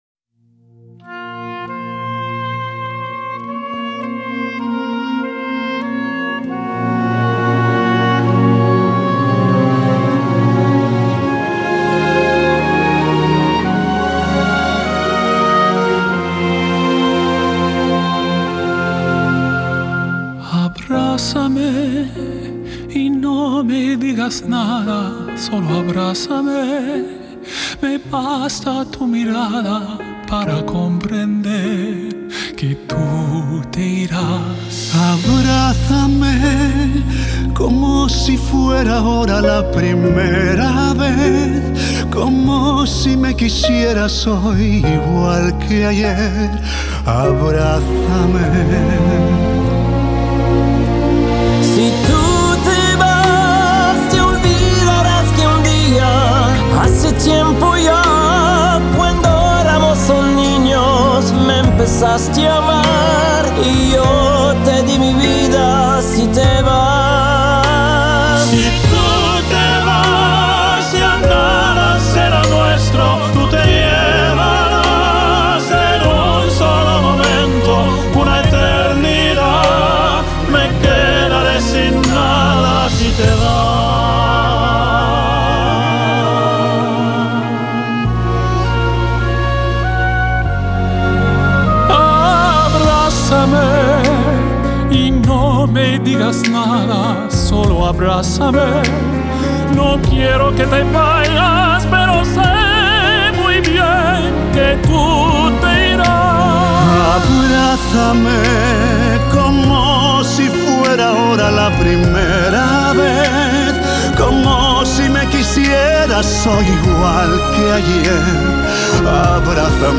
Genre: Classical Crossover